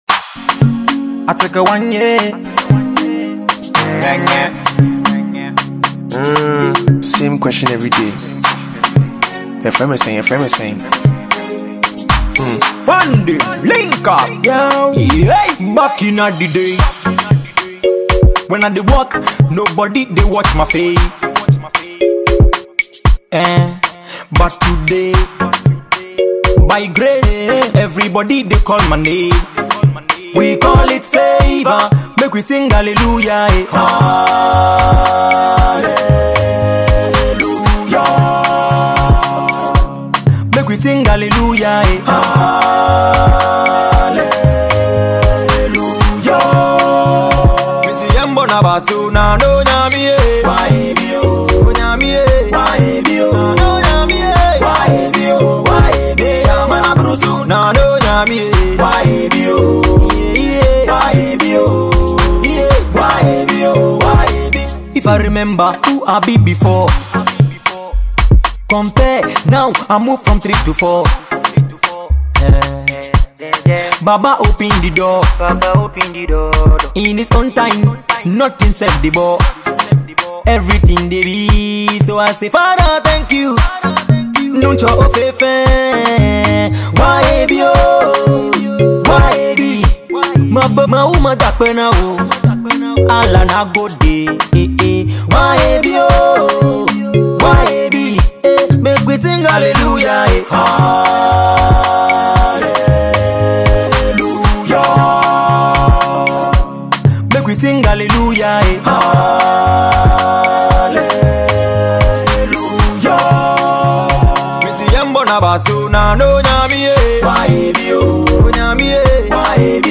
Afrobeat/Afropop